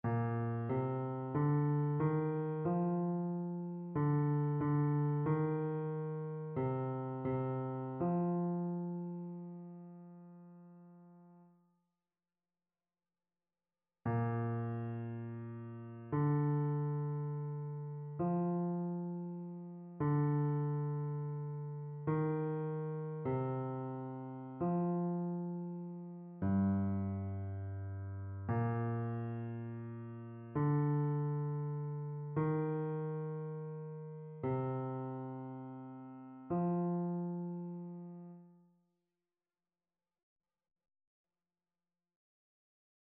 Basse
annee-abc-temps-du-careme-veillee-pascale-psaume-50-basse.mp3